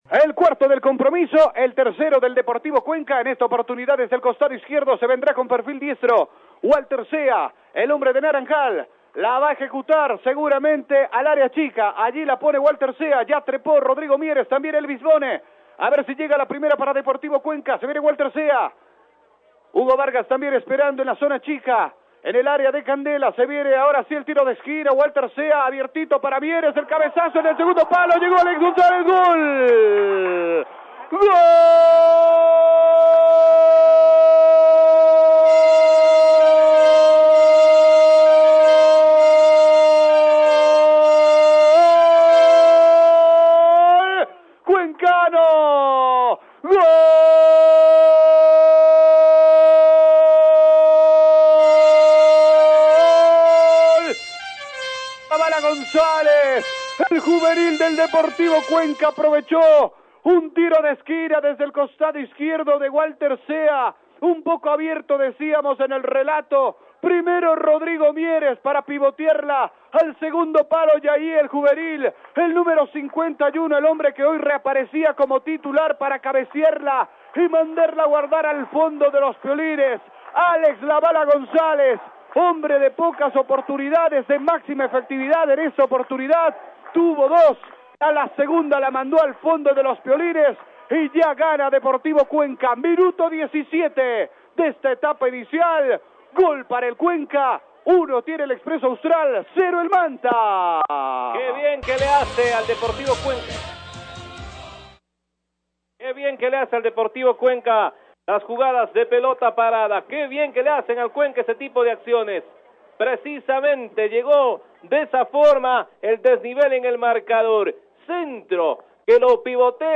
Narración-de-gol-Cuenca-Vs.-Manta.mp3